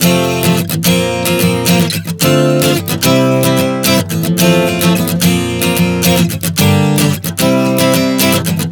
Prog 110 F-Bb-Dm-C [Gm-C].wav